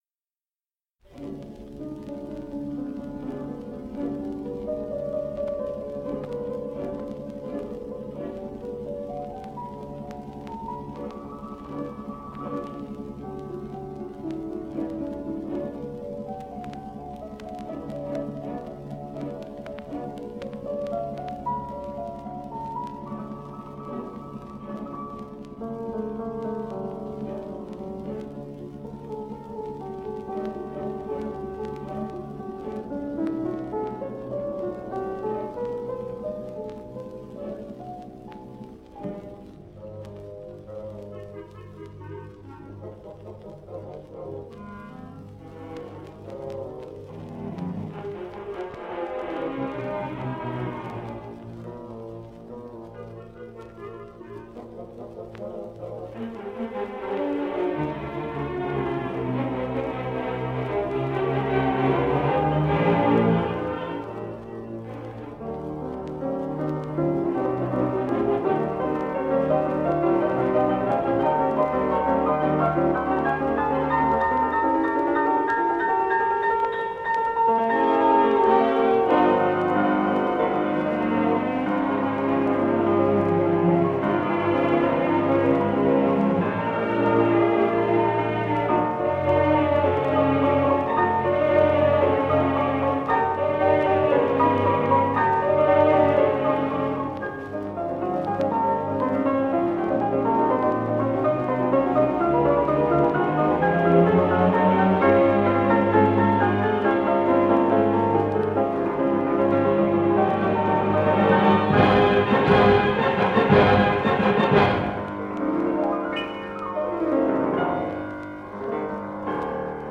Concerto No. 2 in C minor, Op. 18.
The Gramophone Company. 1 disco: 78 rpm ; 30 cm.